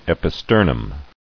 [ep·i·ster·num]